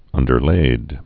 (ŭndər-lād)